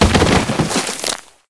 liondead.ogg